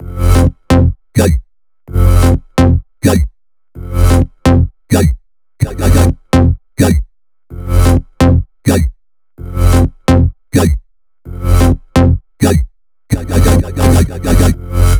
Index of /VEE/VEE2 Melody Kits 128BPM